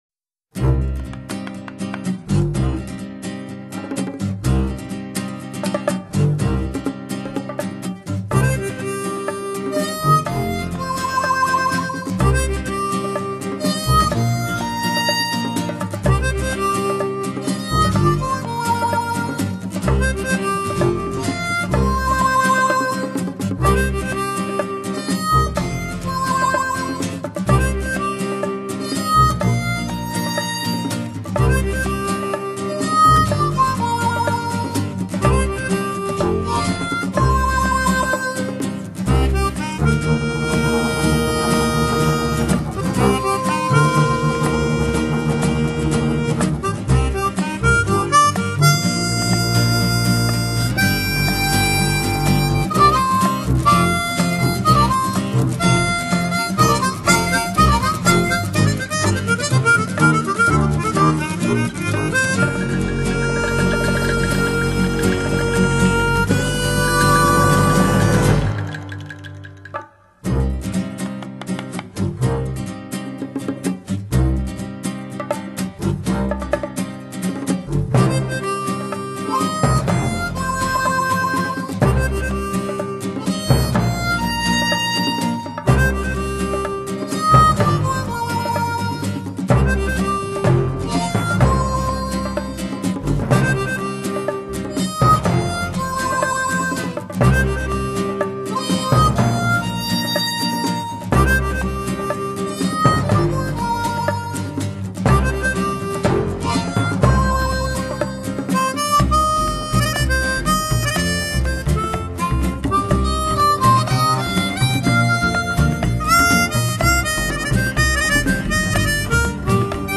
音樂全部是由歷年有名的電影主題改編而來，樂手配合熟練，玩得有聲有色，神采飛揚的節奏和韻味，聽來頗有黑人樂手地道的功架。